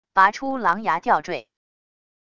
拔出狼牙吊坠wav音频